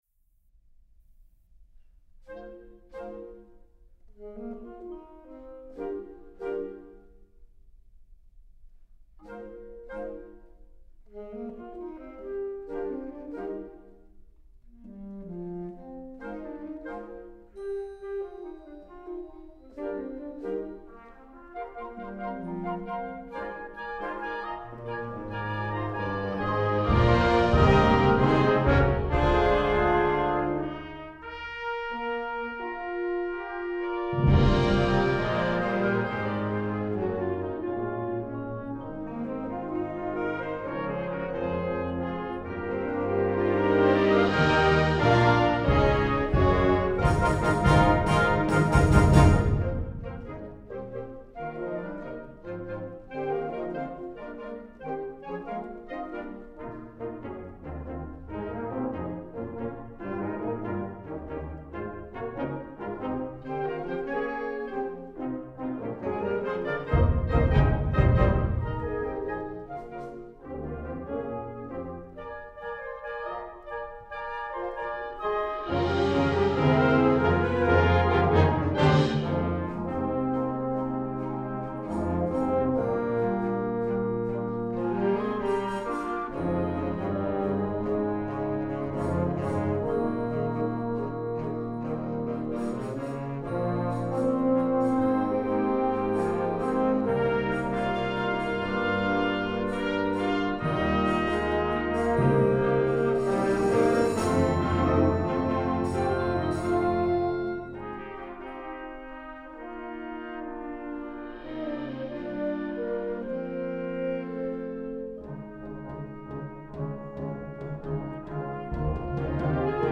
Genre: Band
Euphonium
Timpani (4 drums)